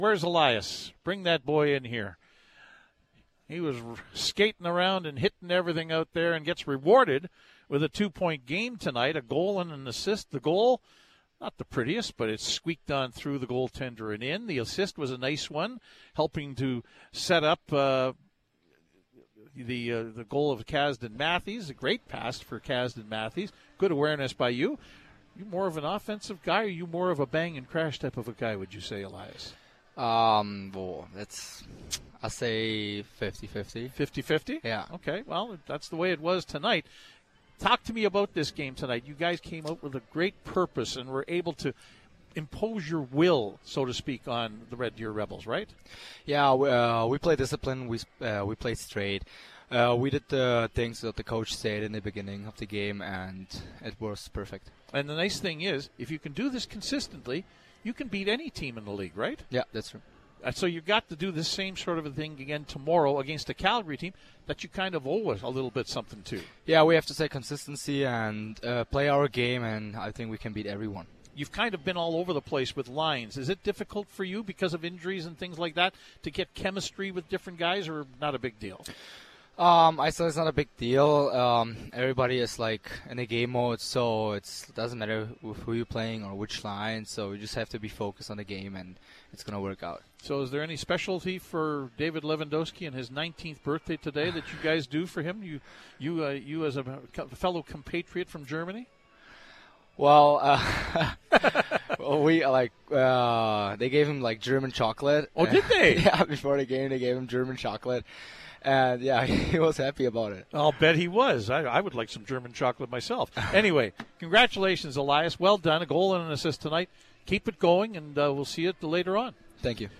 Post-Game Show interviews